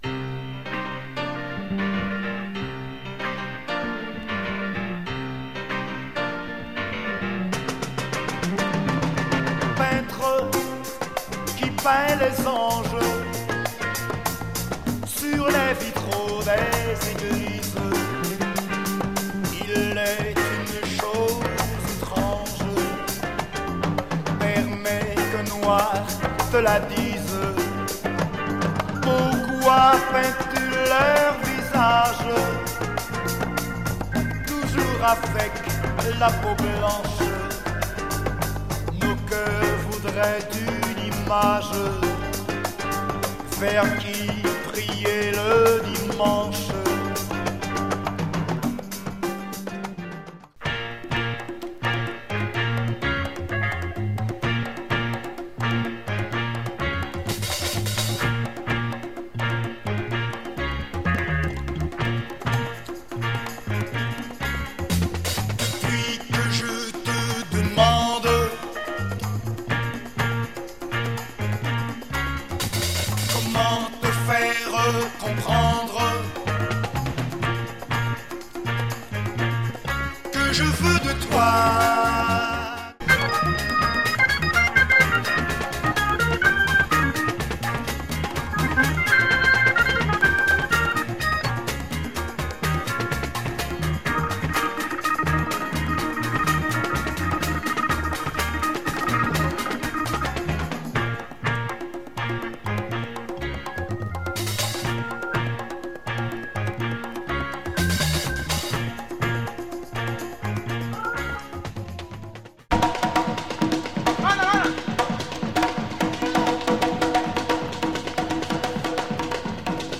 Beautiful and deep Caribbean latin soul jazz.